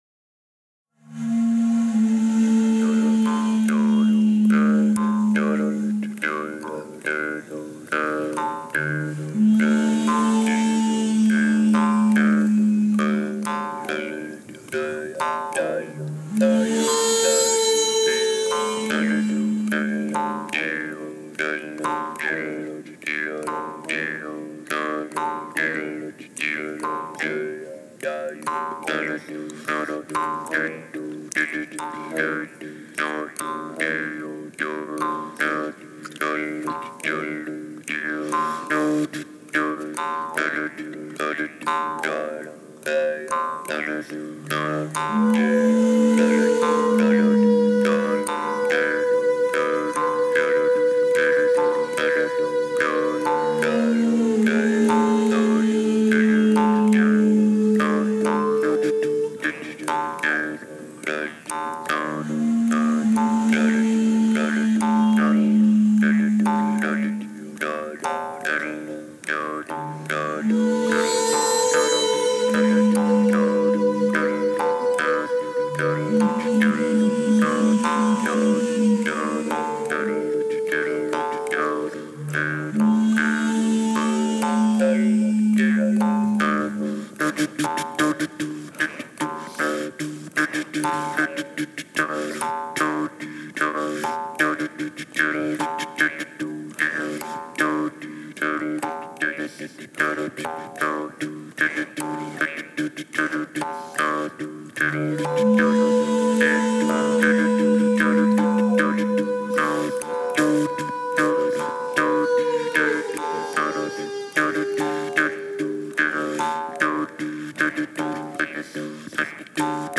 abstract, harmonic vocal pieces
Earthharp, birdsong, insects, wind, thunder and rain.